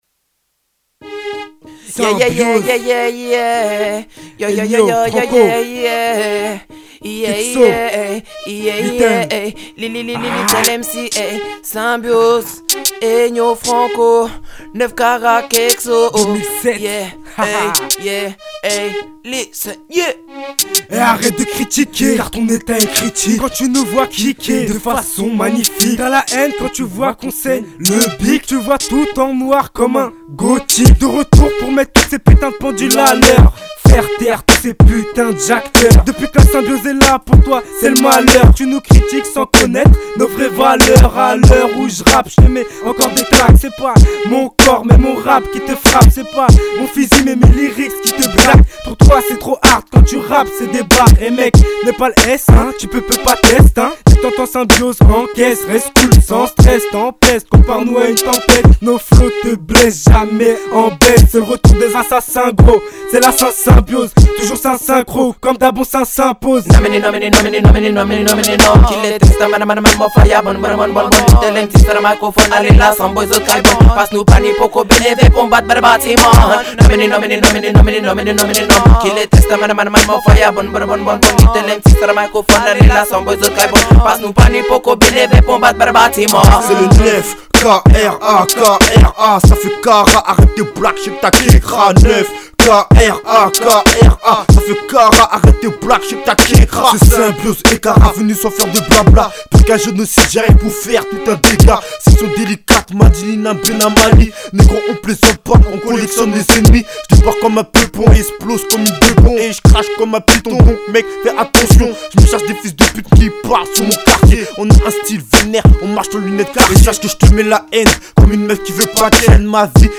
Catégorie : Rap -> Music